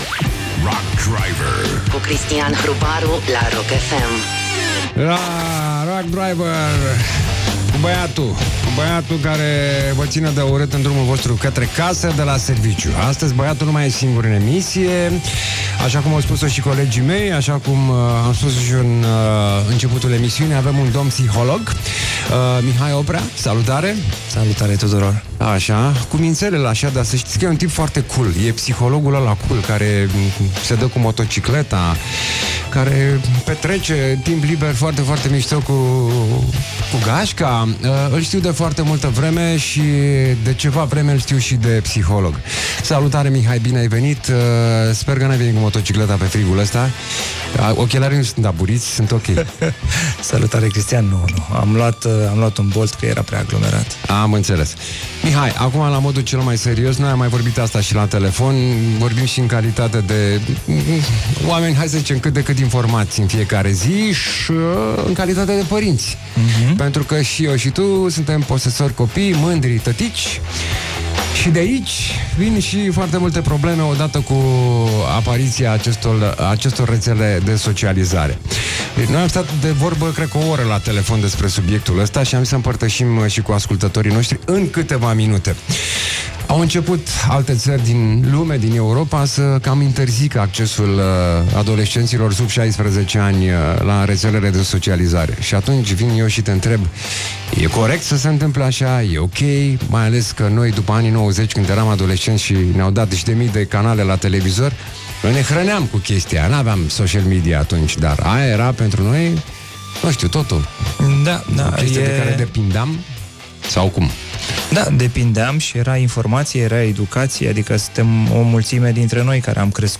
psiholog, despre copii și rețelele sociale